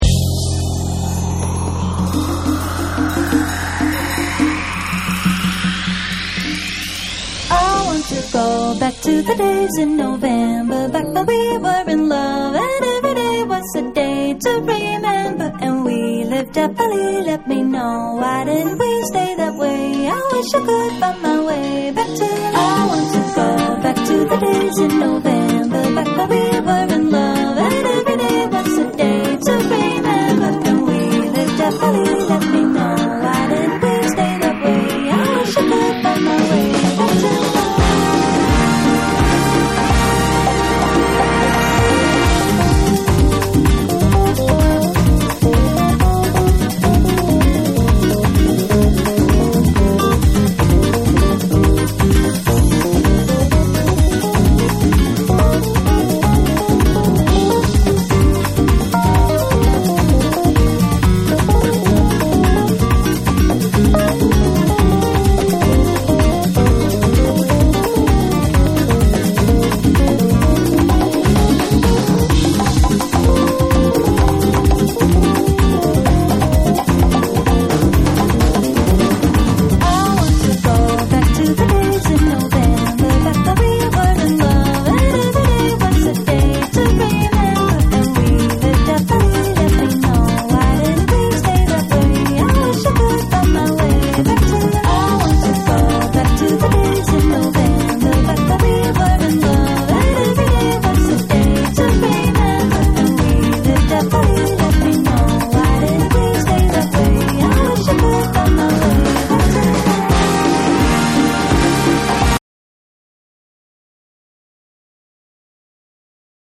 多幸に満ちたブラジリアン・ハウス
BREAKBEATS / SOUL & FUNK & JAZZ & etc